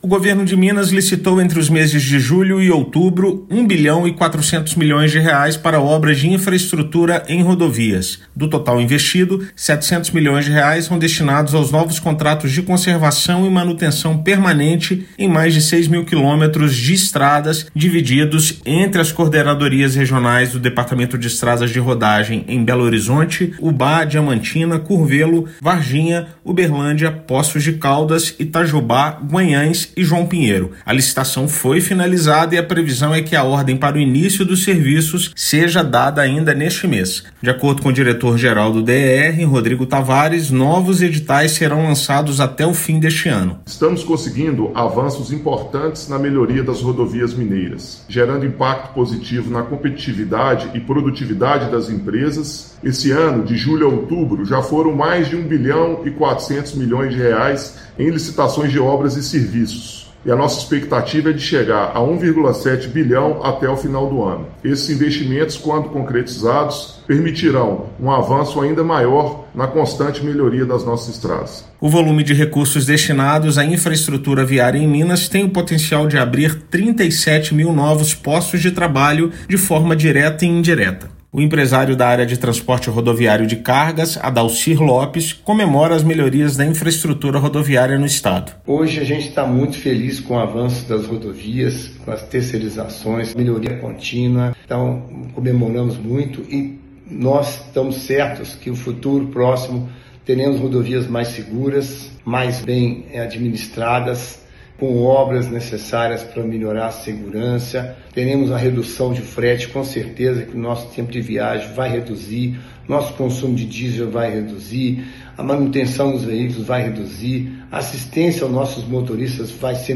Investimento tem potencial para que ocorra a abertura de 37 mil novos postos de trabalho. Ouça matéria de rádio.